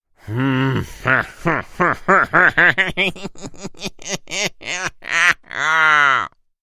На этой странице собраны забавные звуки гномов: смех, шутки, шаги и другие загадочные шумы.
Злобный хохот гнома